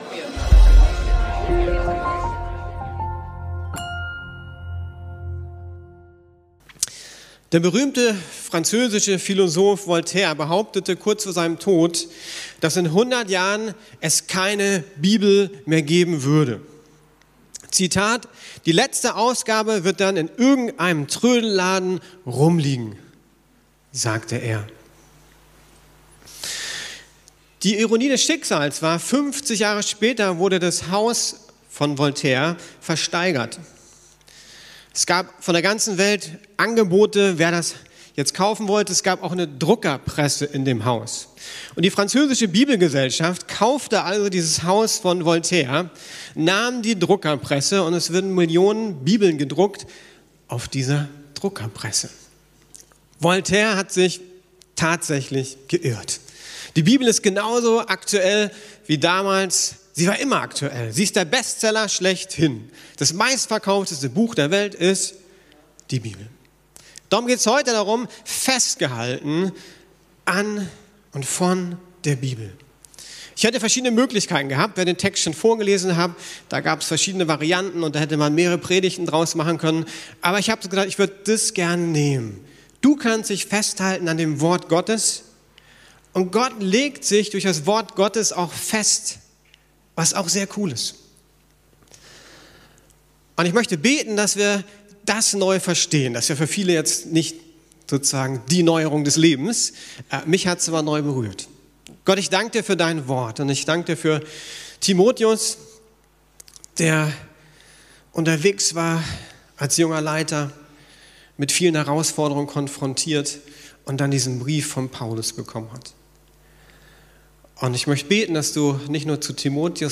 Bleib bei dem, was du gelernt hast! ~ Predigten der LUKAS GEMEINDE Podcast